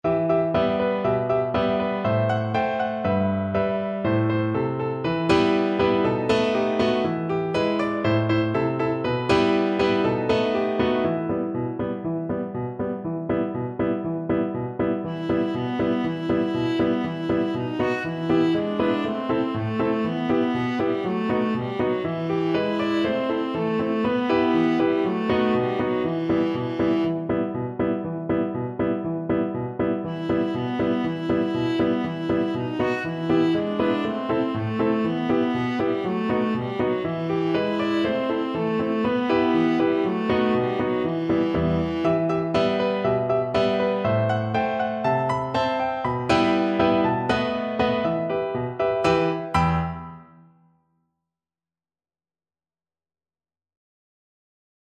Viola version
World Europe Ukraine Oy, Marychko (Ukrainian Trad.)
Viola
E minor (Sounding Pitch) (View more E minor Music for Viola )
2/4 (View more 2/4 Music)
Allegro (View more music marked Allegro)